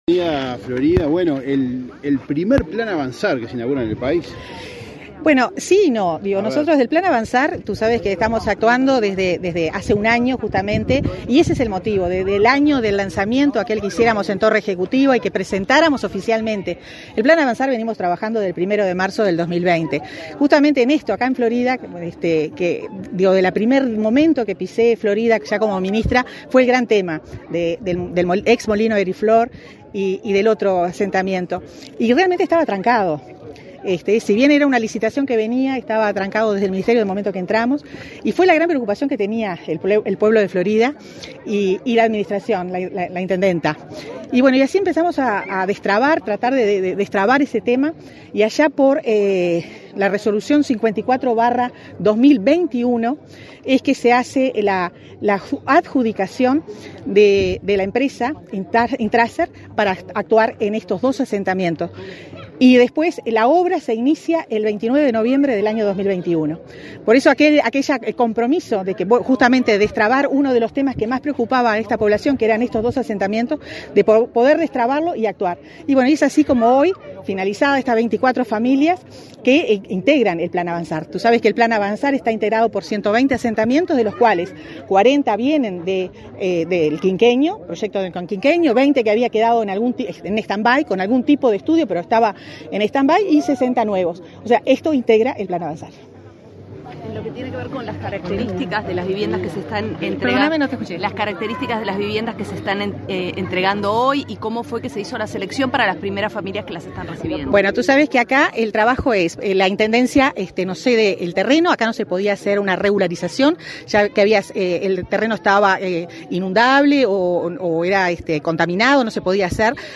Declaraciones a la prensa de la ministra de Vivienda y Ordenamiento Territorial, Irene Moreira
Declaraciones a la prensa de la ministra de Vivienda y Ordenamiento Territorial, Irene Moreira 02/05/2023 Compartir Facebook X Copiar enlace WhatsApp LinkedIn Con la presencia del presidente de la República, Luis Lacalle Pou, Mevir entregó viviendas en el departamento de Florida, este 2 de mayo. En la oportunidad, Moreira realizó declaraciones a la prensa.